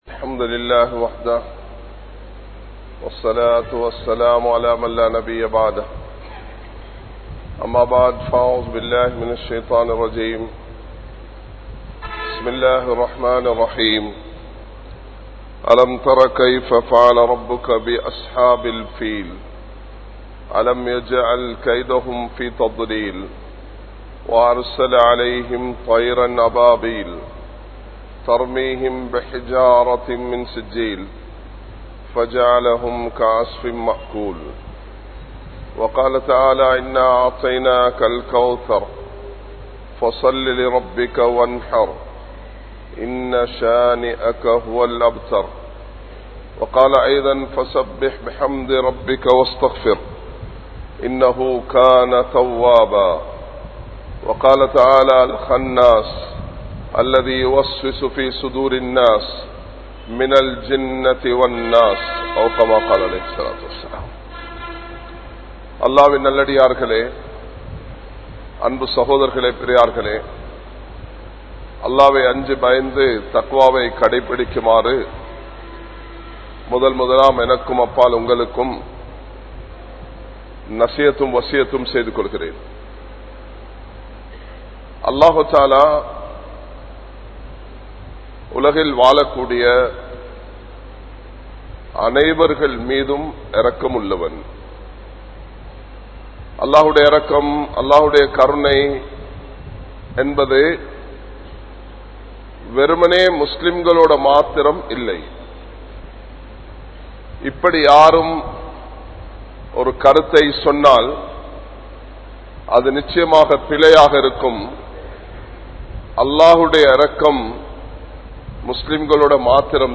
Muslimaha Illaamal Maraniththu Vidatheerhal (முஸ்லிமாக இல்லாமல் மரணித்து விடாதீர்கள்) | Audio Bayans | All Ceylon Muslim Youth Community | Addalaichenai